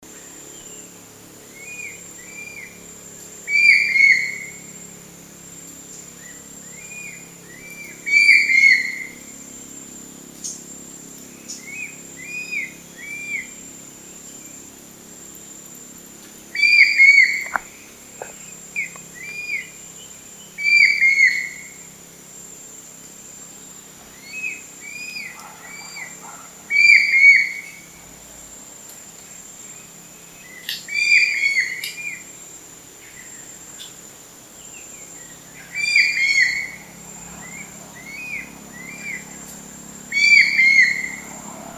brown-winged-kingfisher-call